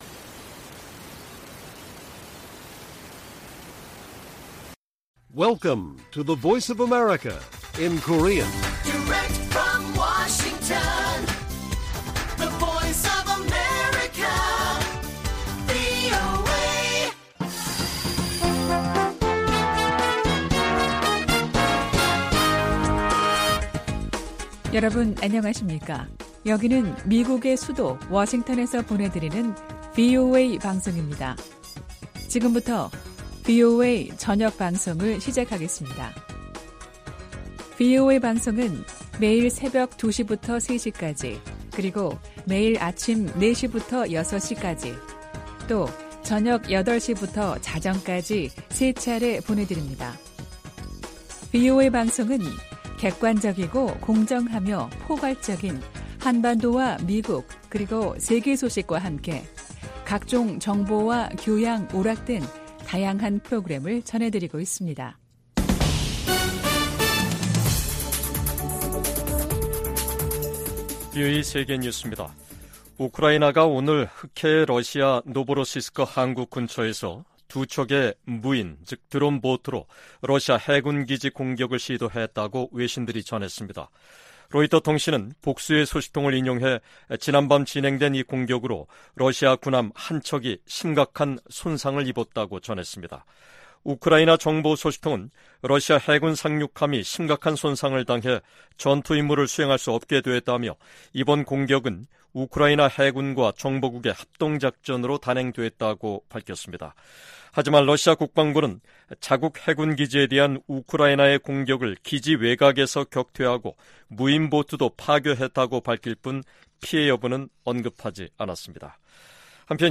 VOA 한국어 간판 뉴스 프로그램 '뉴스 투데이', 2023년 8월 4일 1부 방송입니다. 오는 18일 미한일 정상회의에서 북한의 미사일 방어 등 3국 안보 협력을 강화하는 방안이 논의될 것이라고 한국 국가안보실장이 밝혔습니다. 러시아 국방장관의 최근 평양 방문은 군사장비를 계속 획득하기 위한 것이라고 백악관 고위관리가 지적했습니다. 토니 블링컨 미 국무장관은 북한이 월북 미군의 행방과 안위 등에 관해 답변을 하지 않았다고 말했습니다.